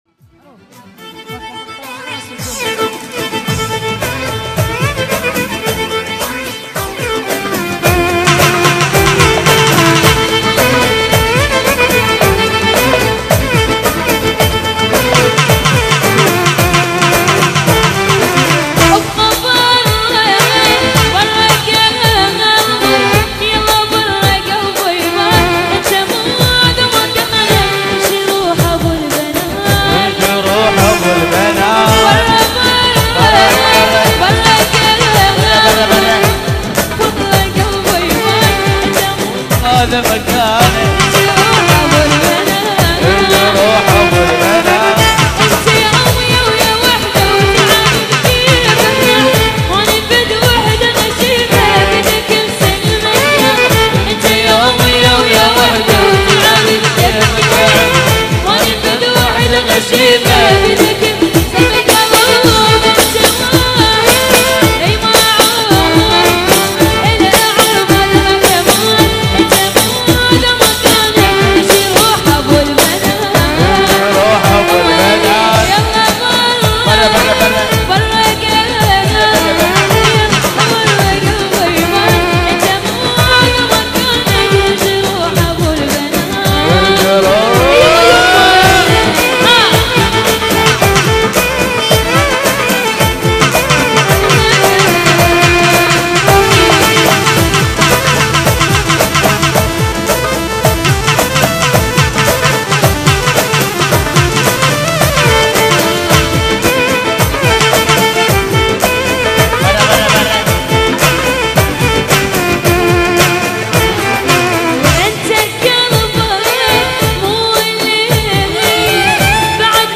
حفلة